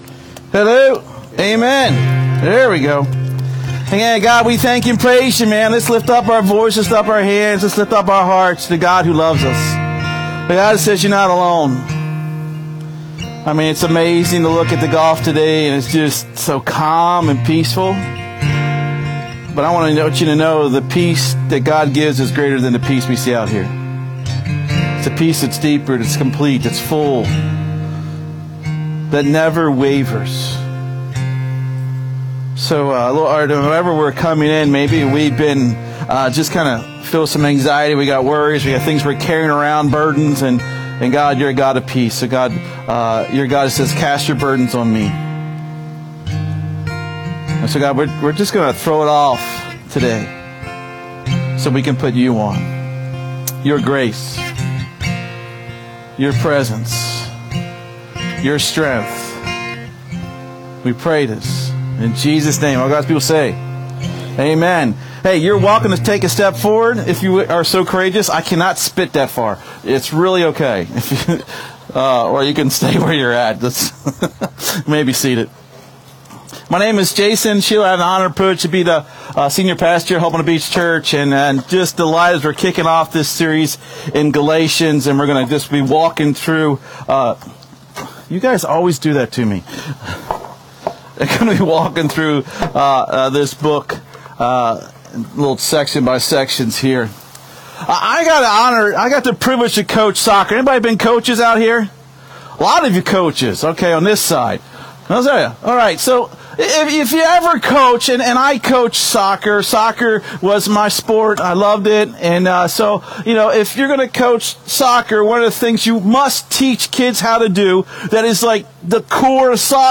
SERMON DESCRIPTION As we start our series on the book of Galatians we must address an important issue that has been plaguing the churches in that region.